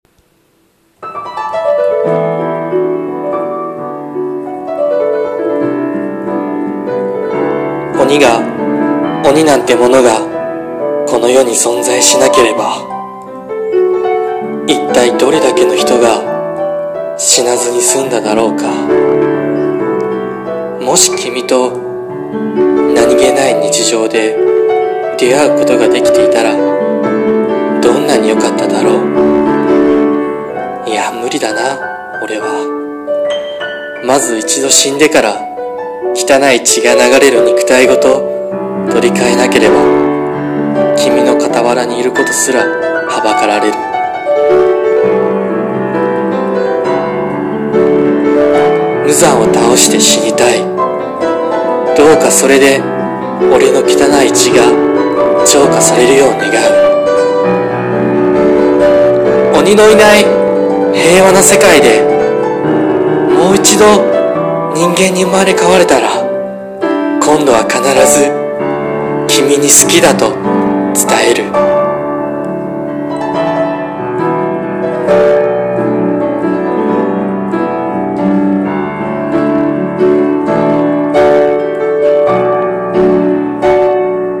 声劇】鬼滅の刃 蛇柱:伊黒小芭内